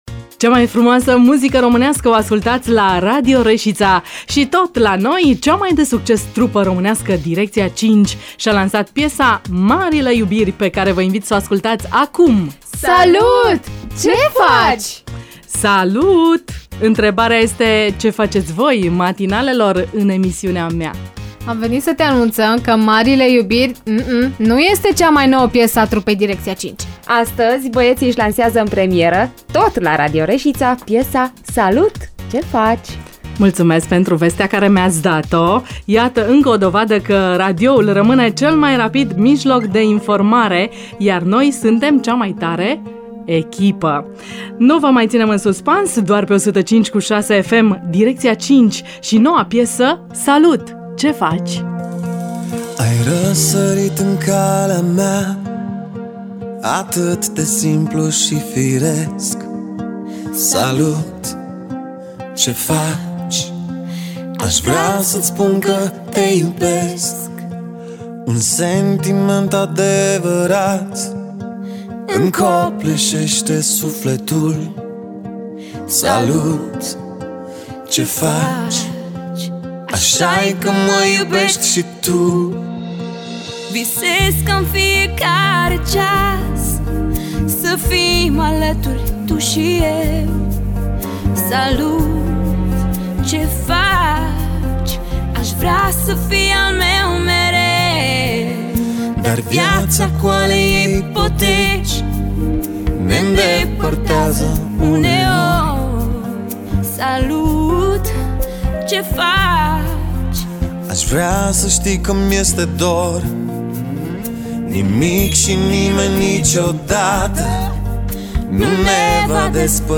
Marian Ionescu a declarat în DIRECT la Radio România Reşiţa că piesa Salut, ce faci?  va fi lansată oficial în luna aprilie. Piesa vorbeşte despre dragoste, prietenie şi minunea de a fi împreună cu persoana iubită.